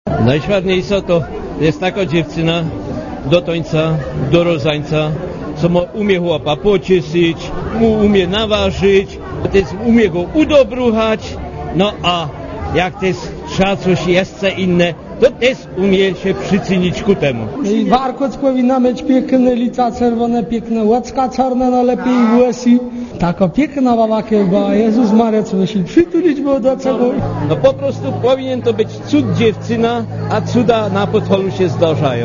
Posłuchaj, jak idealną kobietę wyobrażają sobie Górale